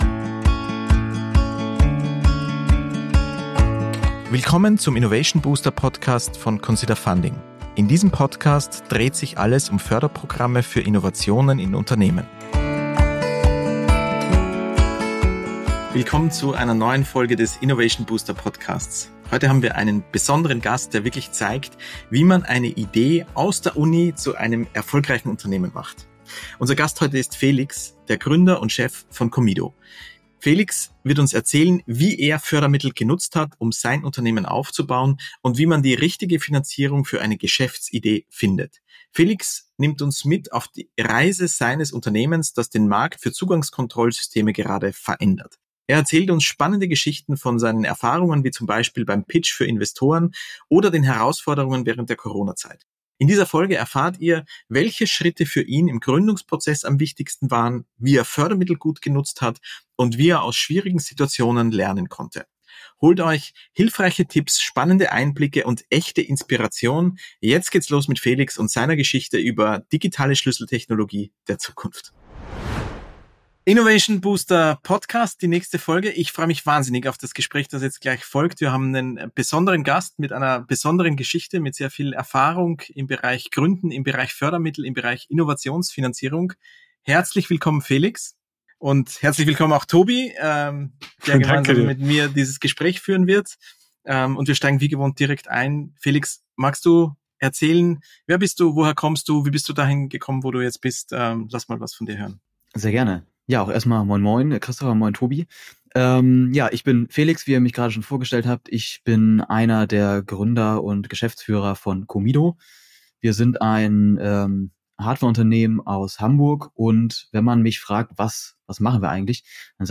ein Best-Practice-Gesprächspartner zu Gast